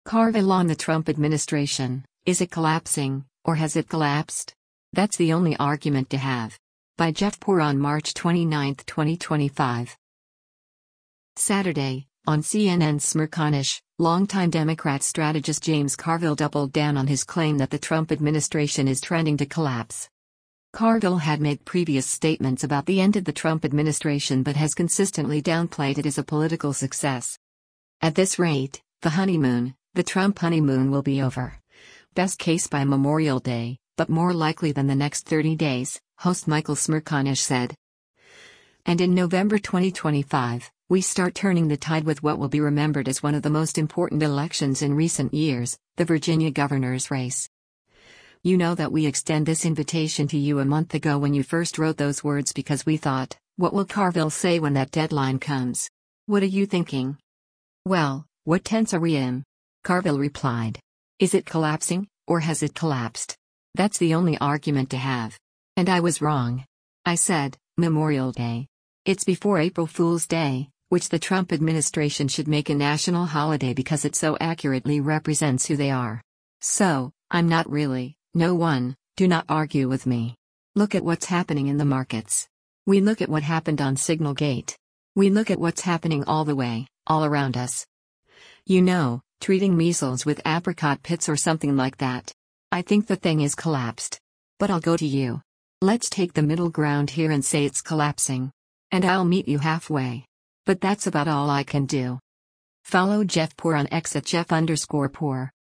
Saturday, on CNN’s “Smerconish,” longtime Democrat strategist James Carville doubled down on his claim that the Trump administration is trending to “collapse.”